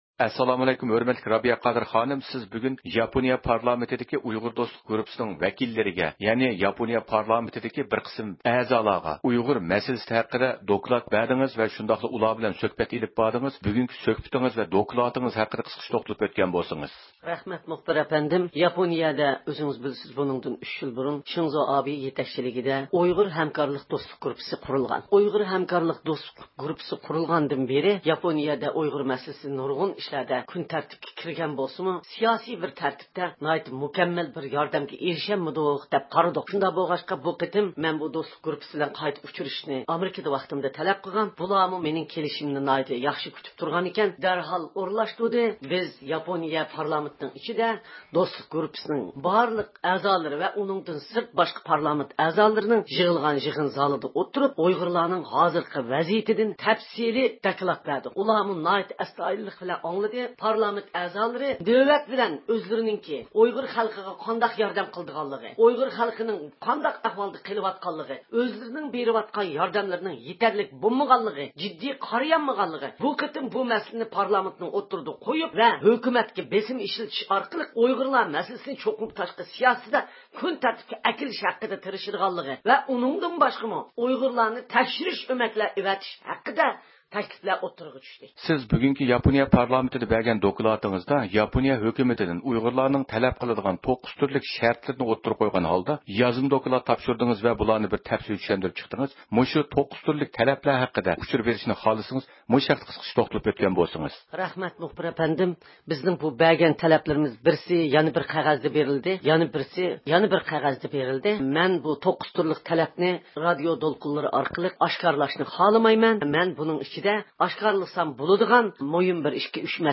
دىققىتىڭلار، رابىيە قادىر خانىم بىلەن مەزكۇر دوكلات ھەققىدە ئېلىپ بارغان سۆھبىتىمىزدە بولغاي.